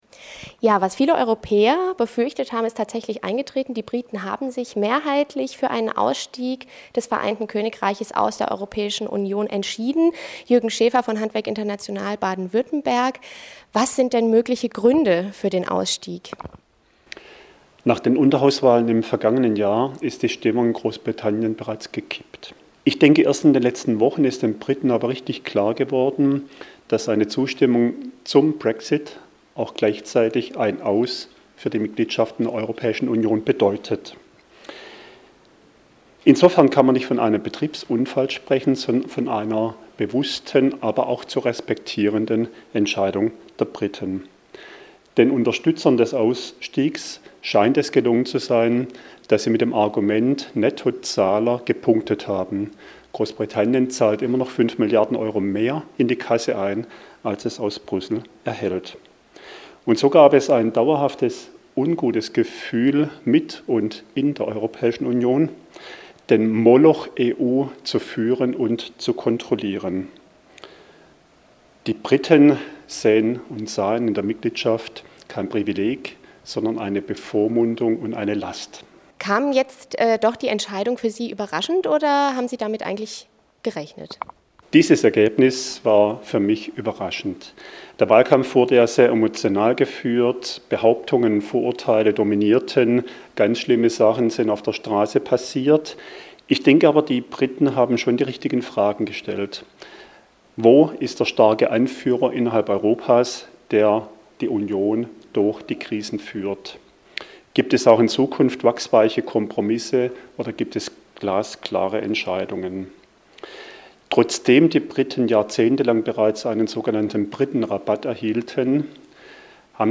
brexit-interview